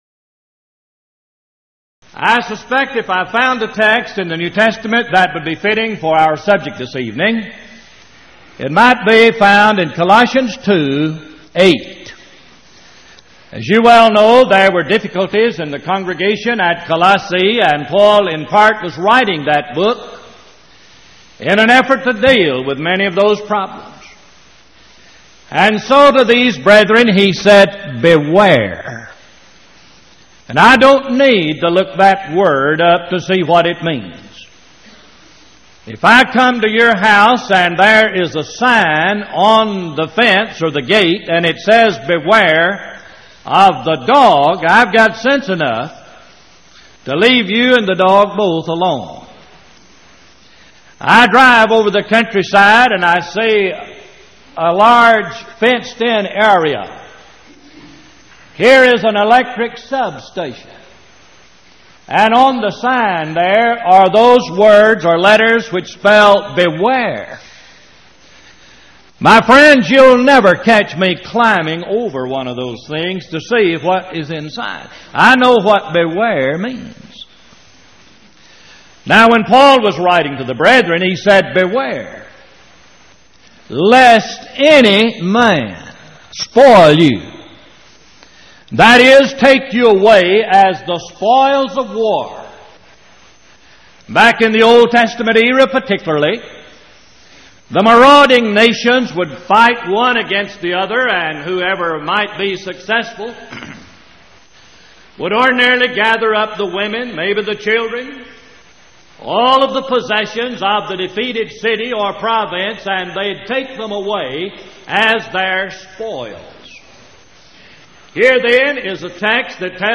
Event: 1989 Power Lectures Theme/Title: The Providence of God